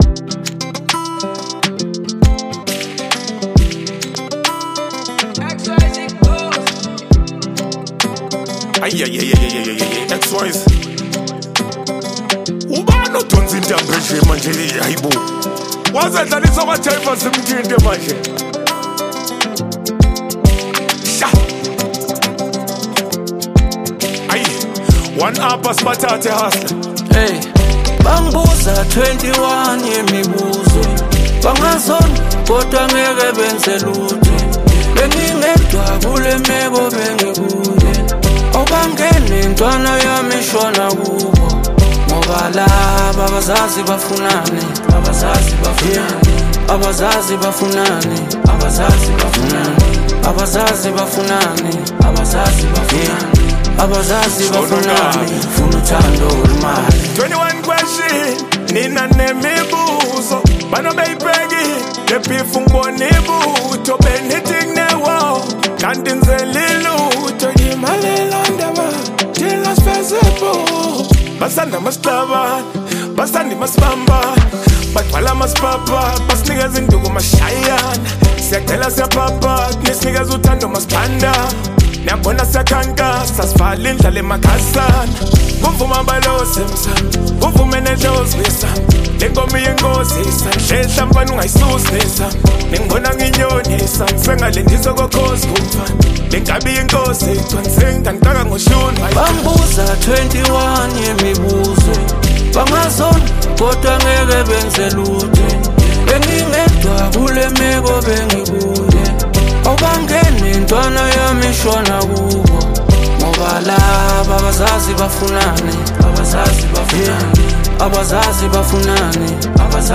South African rapper